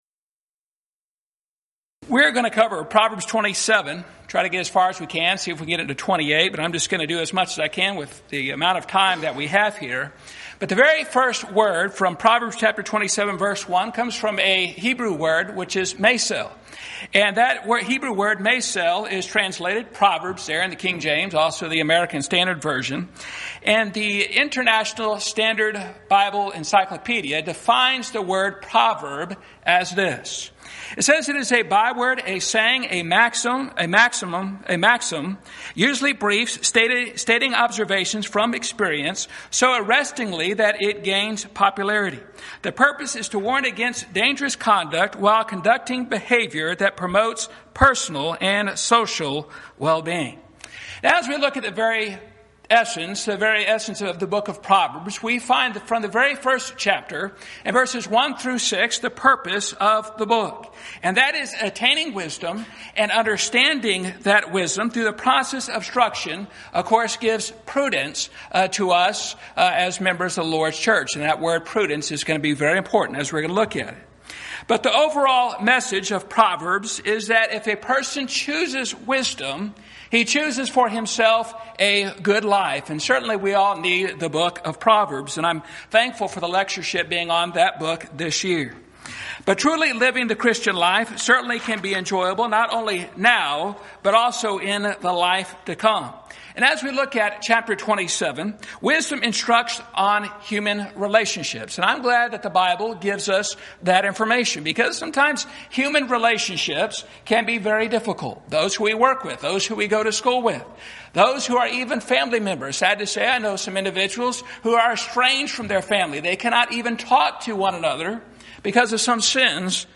Series: Schertz Lectureship Event: 13th Annual Schertz Lectures Theme/Title: Studies in Proverbs, Ecclesiastes, & Song of Solomon